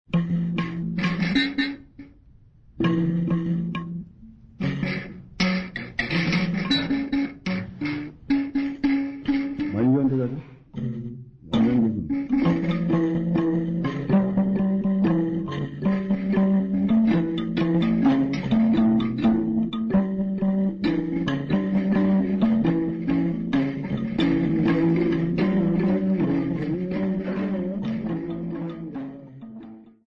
Sambiu church music workshop participants
Sacred music Namibia
Mbira music Namibia
field recordings
Church song accompanied by the mbira type instrument sisanti and indingo played at both lower and upper key.